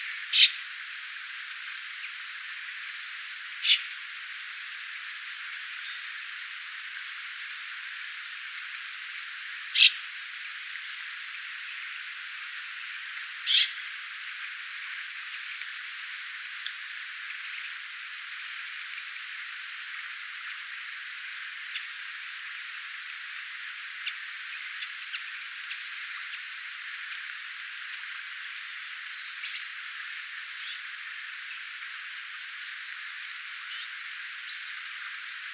erikoisia nuoren järripeipon ääniä
erikoisia_jarripeipon_aania_oletettavasti_nuori_lintu.mp3